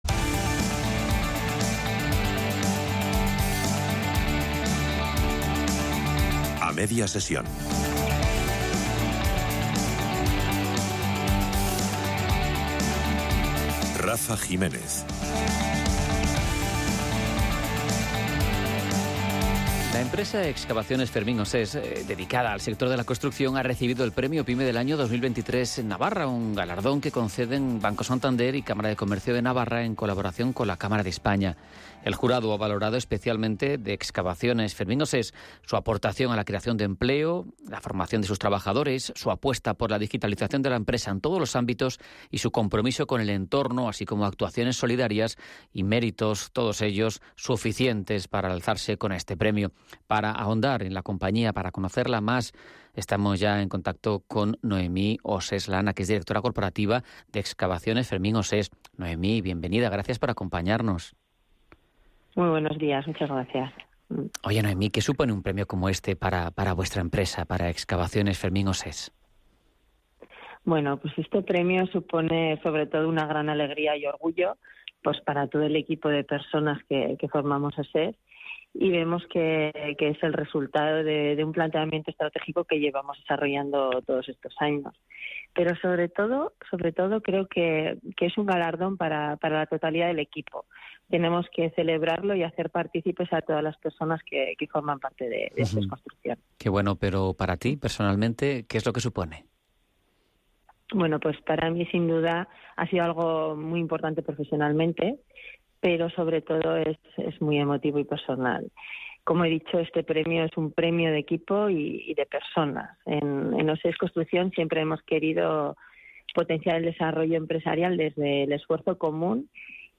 Entrevista-Radio-Intereconomia-19-09.mp3